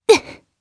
Lewsia_B-Vox_Damage_jp_01.wav